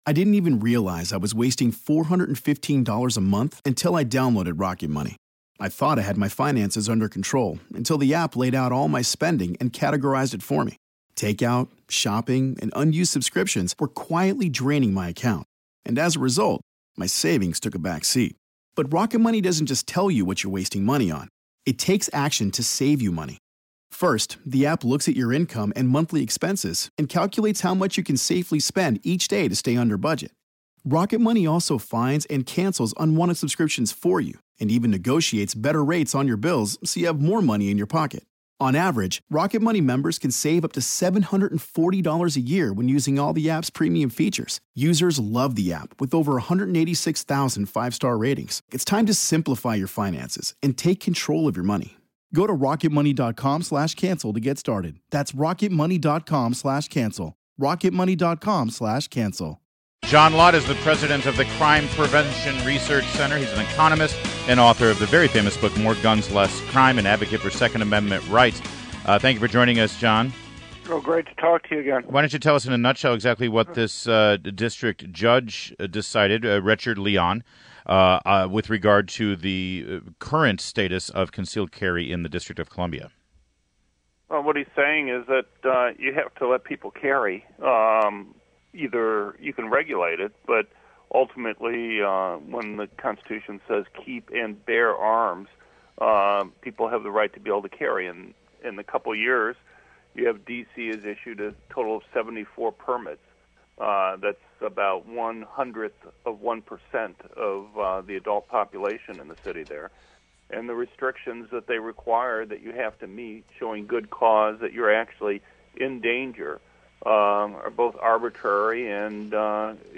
WMAL Interview - JOHN LOTT 05.18.16
INTERVIEW - JOHN LOTT- President, Crime Prevention Research Center (CPRC), economist and author of the famous book "More Guns, Less Crime"